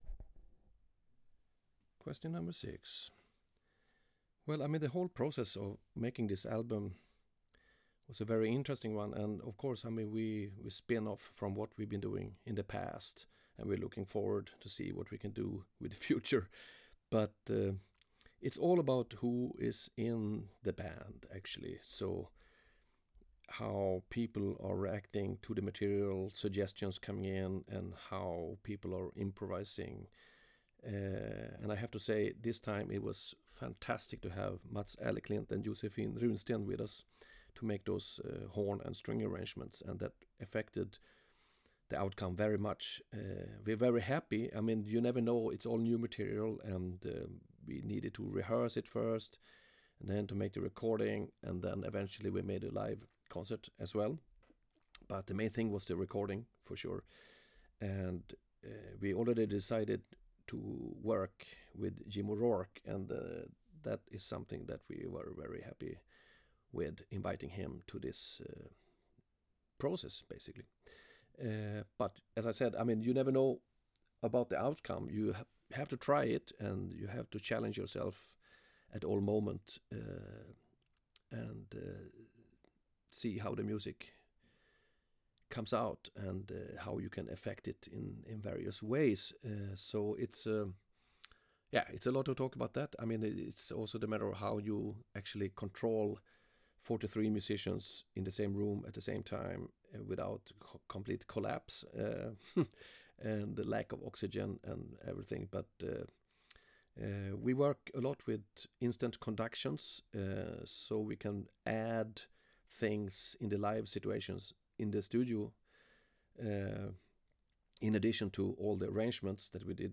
More snippets from my Mats Gustafsson interview